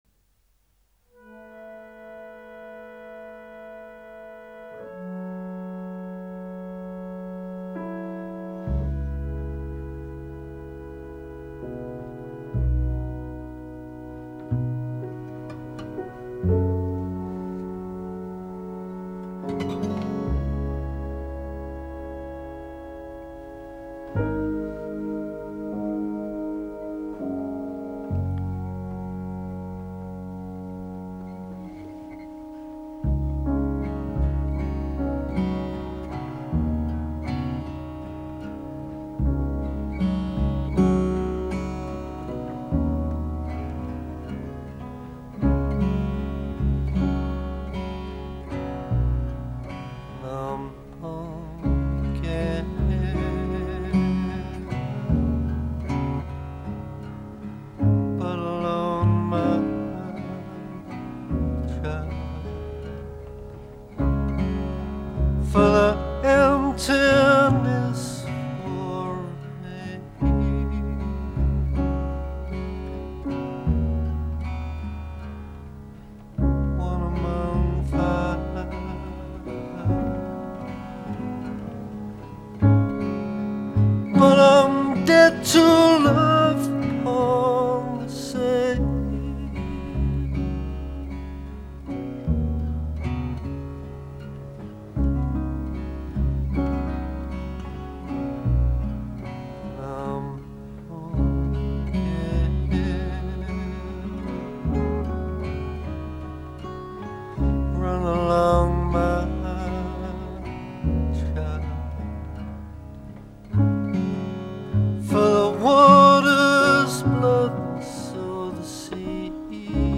And clarinets.
It had something very English about it.
And you sing it so well. With soul.